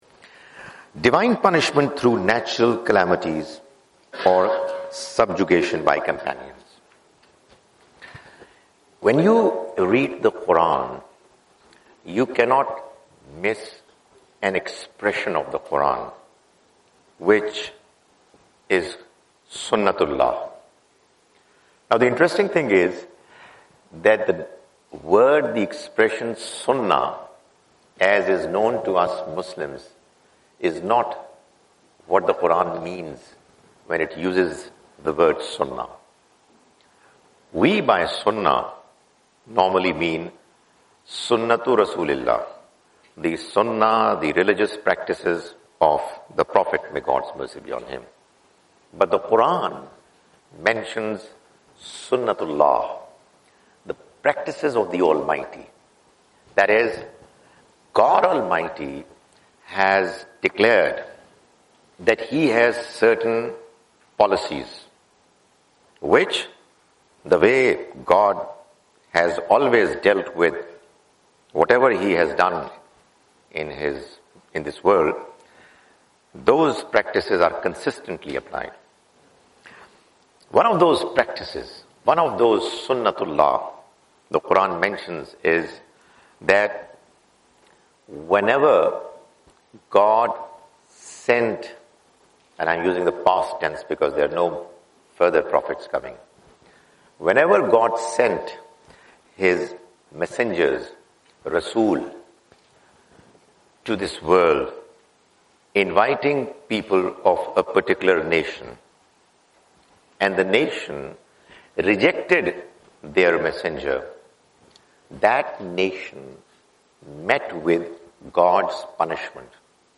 A series of 9 Lecture
Australian Tour 2017: Sydney - Canberra - Melbourne